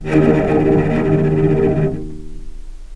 vc_trm-D#2-pp.aif